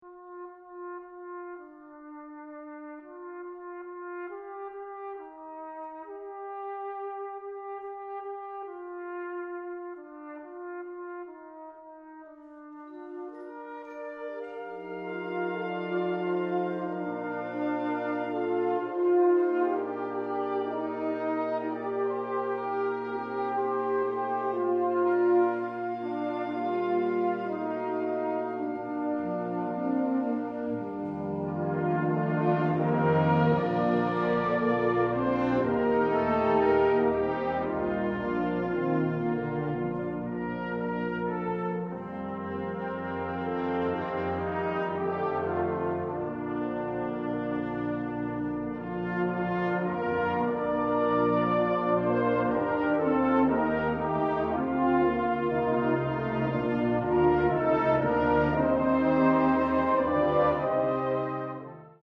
The Swedish hymntune